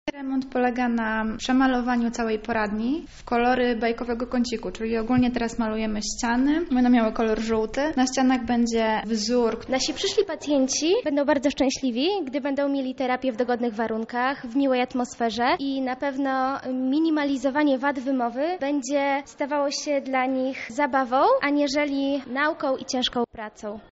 O szczegółach remontu mówią same zainteresowane.